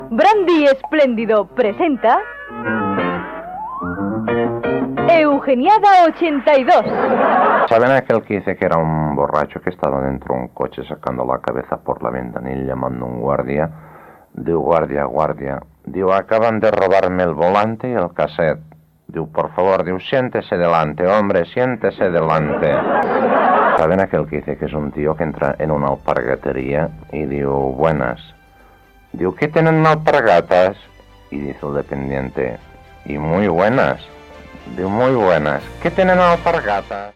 Indicatiu del programa amb publicitat i dos acudits explicats per Eugenio
Entreteniment